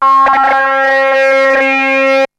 E T TALKS 4.wav